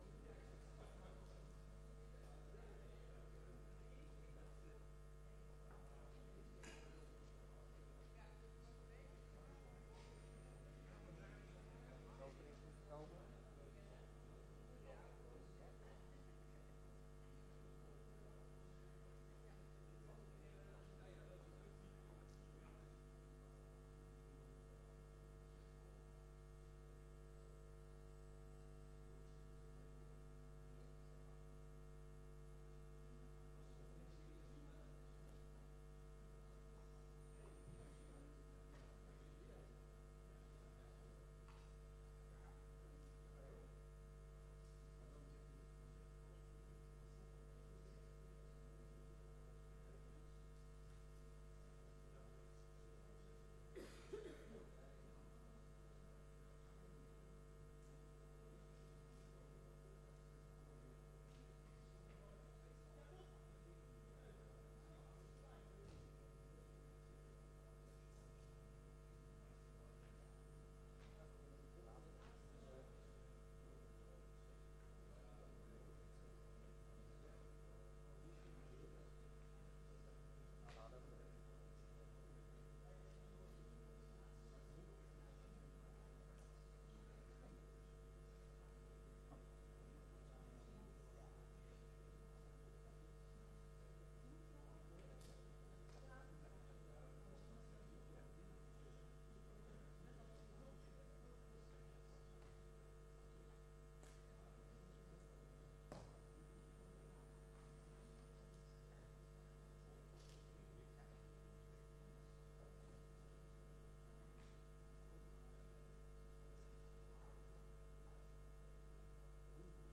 Integrale beeldvormende sessie 06 april 2022 15:30:00, Provincie Flevoland
Locatie: Statenzaal